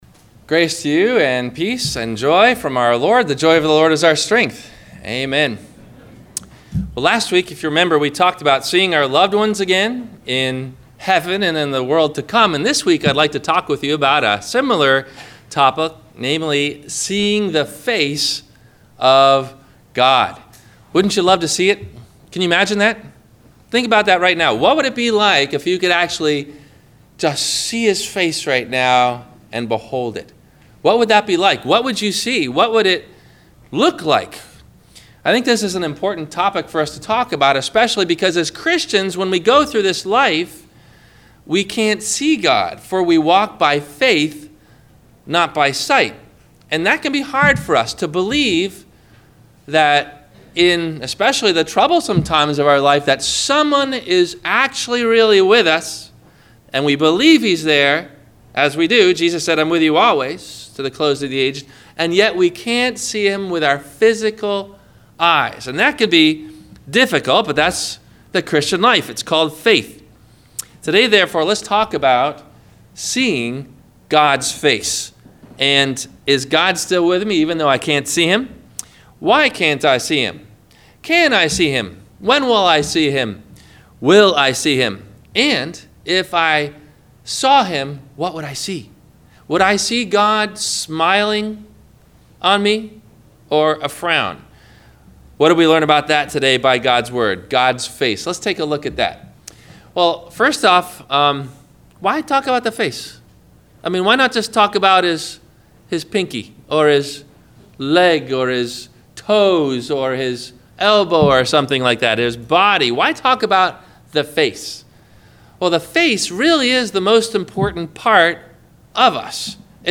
- Sermon - May 14 2017 - Christ Lutheran Cape Canaveral